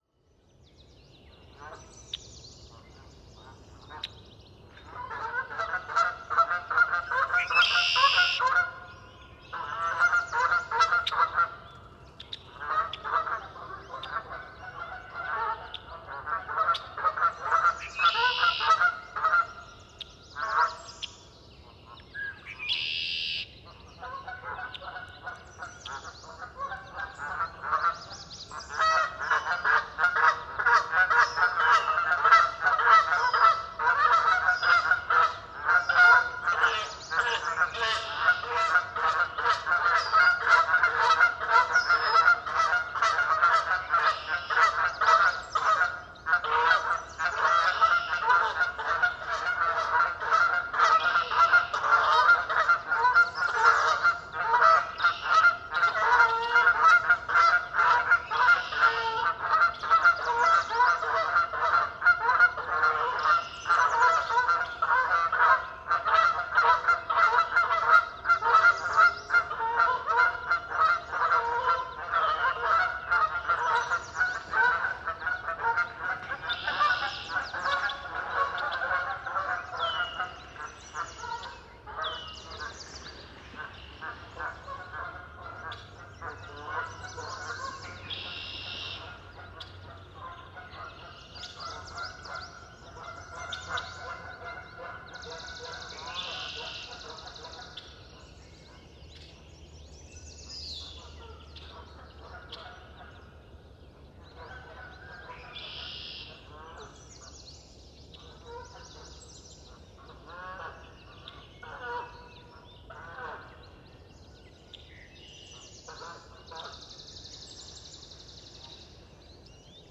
Bernache du Canada – Branta canadensis
Île Saint-Joseph Laval, QC
3710a-loiseau-son-bernaches_du_canada_ile_saint_joseph_laval.m4a